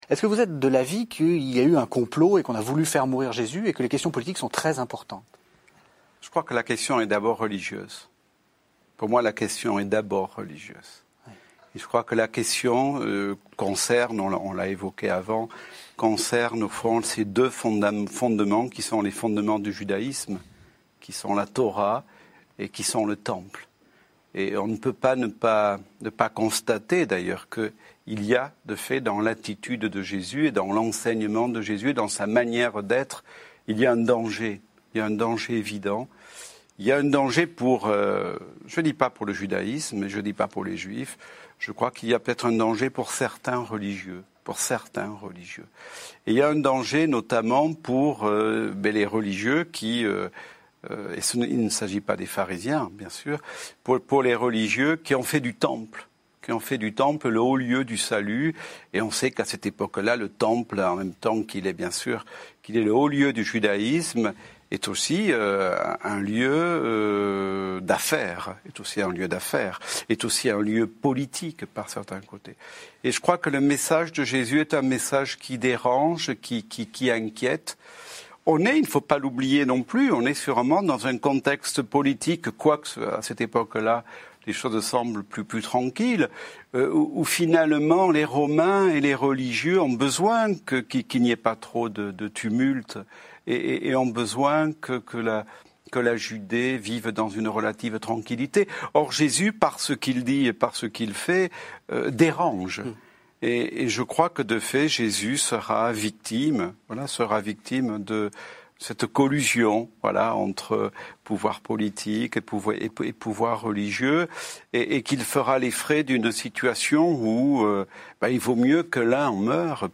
→ A ECOUTER Jésus a été victime des religieux du Temple (Extrait d’une émission de KTO 2’28)